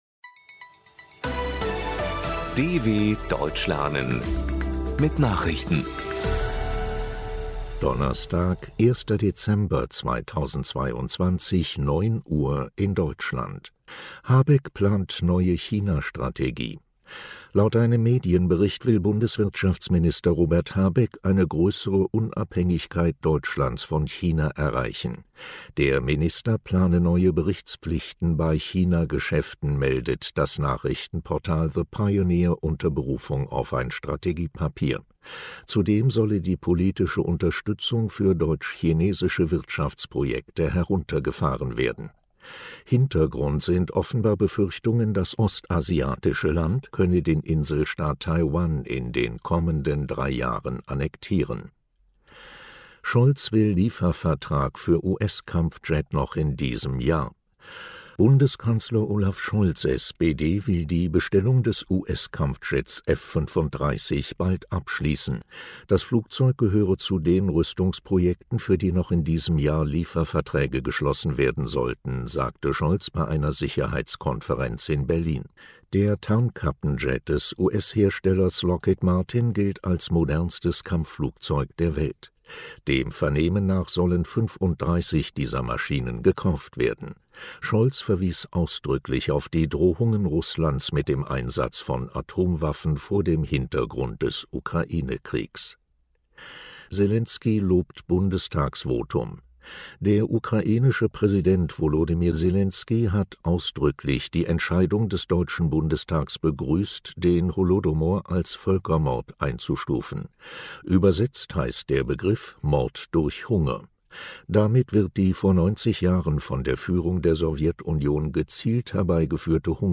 01.12.2022 – Langsam gesprochene Nachrichten
Trainiere dein Hörverstehen mit den Nachrichten der Deutschen Welle von Donnerstag – als Text und als verständlich gesprochene Audio-Datei.